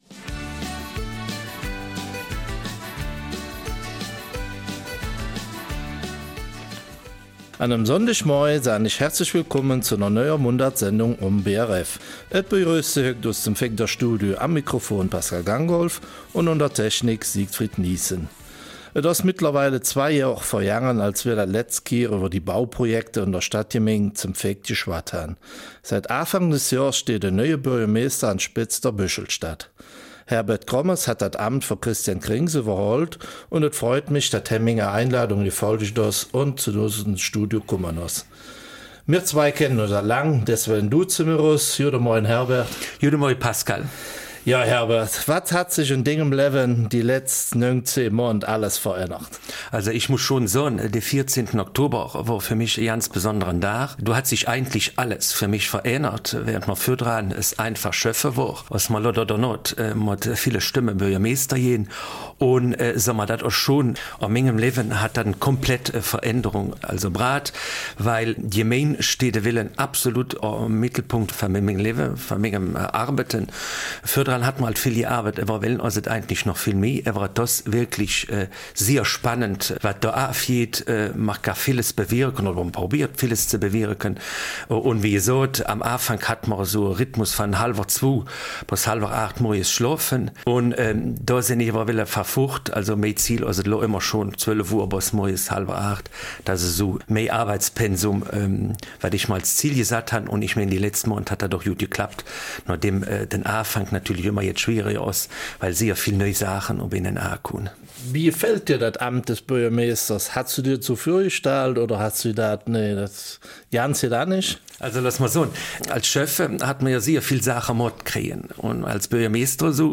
Eifeler Mundart: Neues aus St.Vith